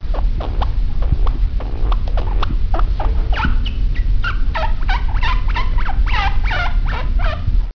Малыш шиншиллы громко пищит